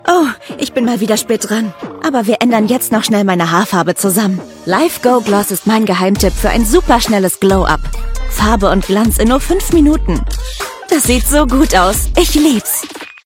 hell, fein, zart, sehr variabel, markant
Jung (18-30)
Commercial (Werbung), Narrative, Off